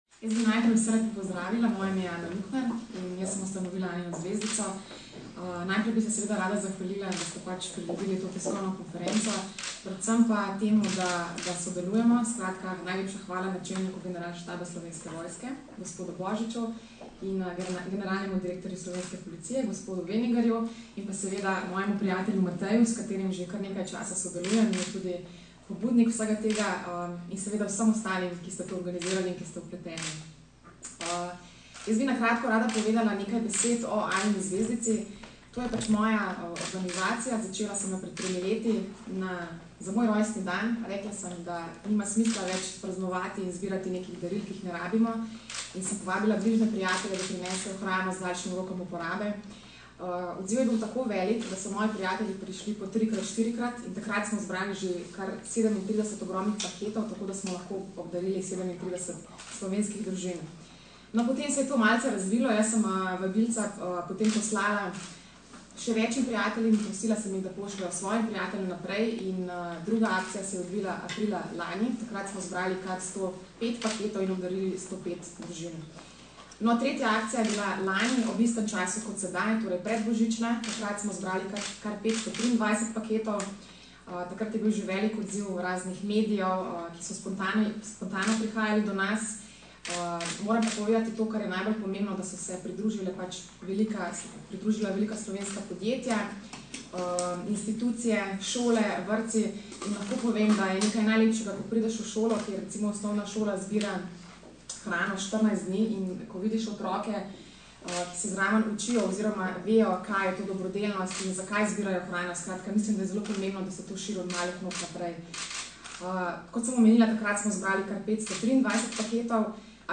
Sodelovanje smo predstavili na današnji novinarski konferenci.